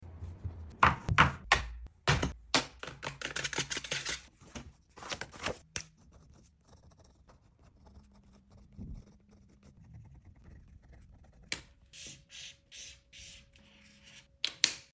Studytok ASMR, I’m Back :) Sound Effects Free Download